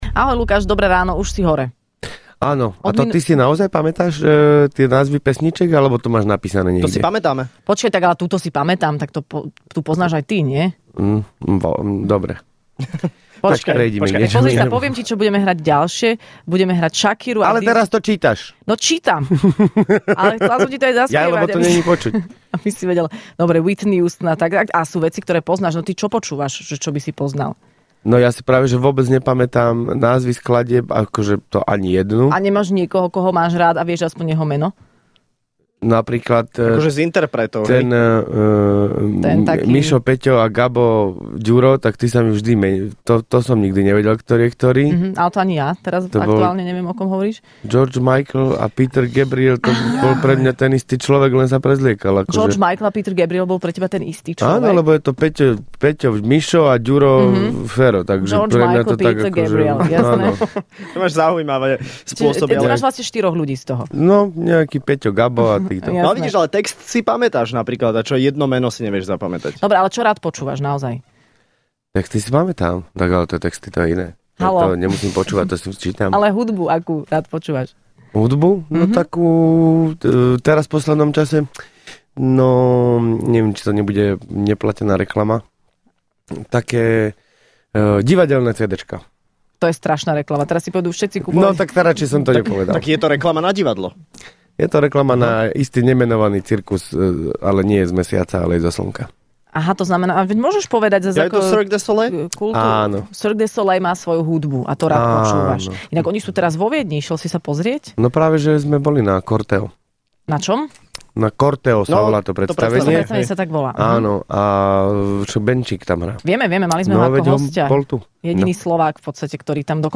Hosťom v Rannej šou bol absolútny víťaz ocenenia OTO Lukáš Latinák.